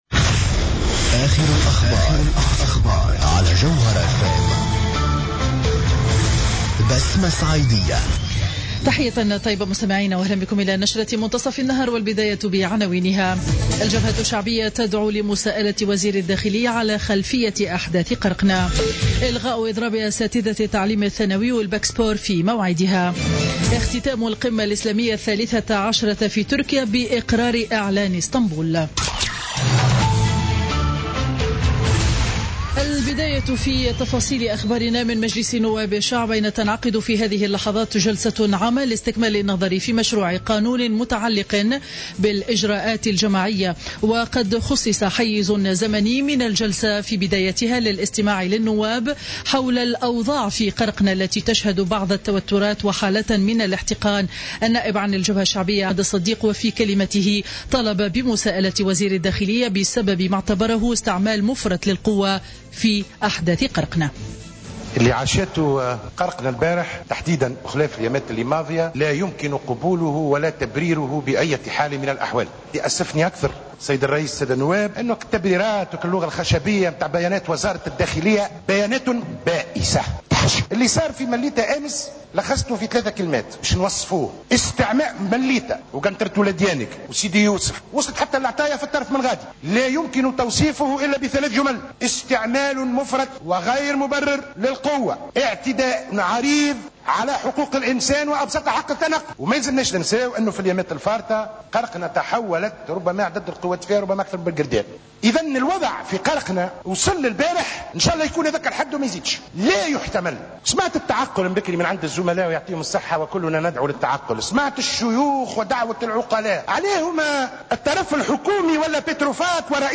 نشرة أخبار منتصف النهار ليوم الجمعة 15 أفريل 2016